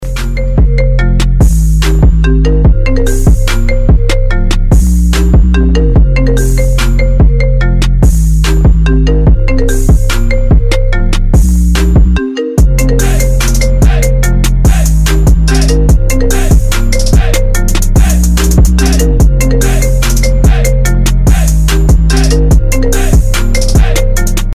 Оригинальный рингтон в стиле trap remix